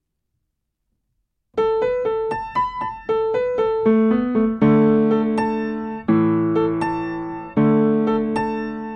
III część: 40 BMP
Nagrania dokonane na pianinie Yamaha P2, strój 440Hz
piano